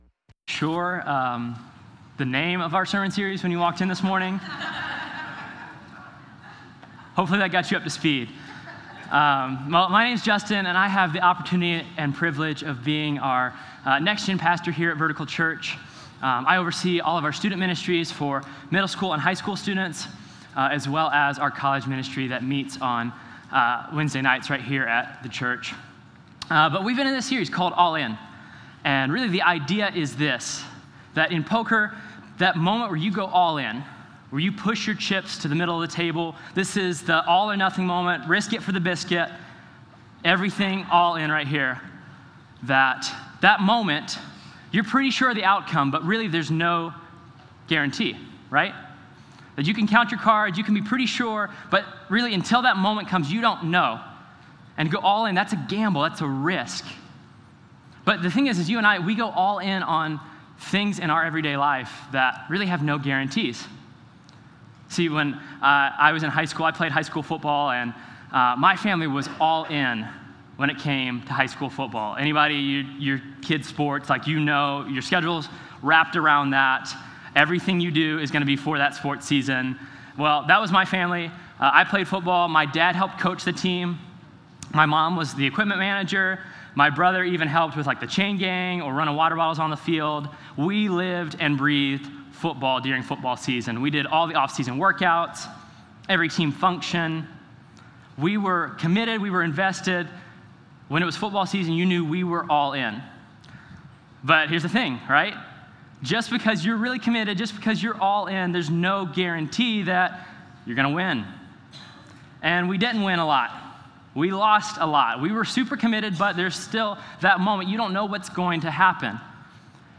Sermon0930_SmallGroups.mp3